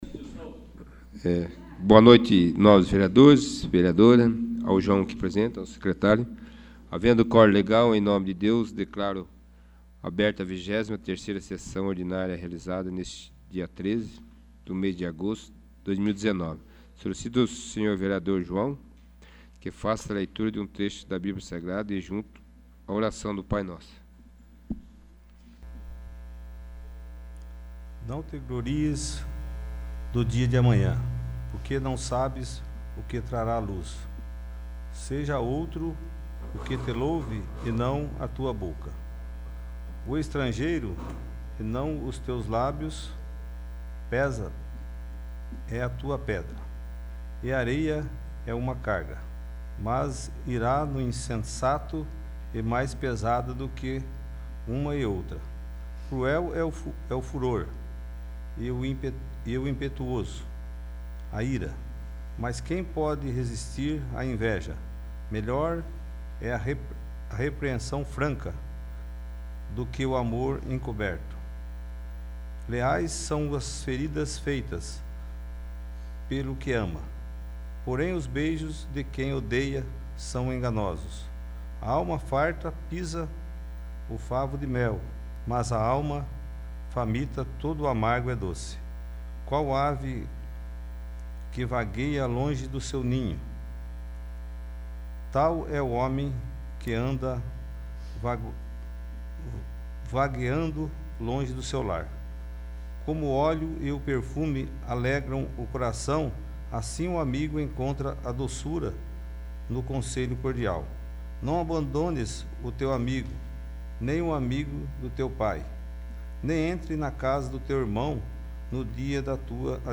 23º. Sessão Ordinária